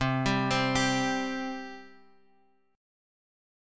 C5 chord {x 3 5 5 x 3} chord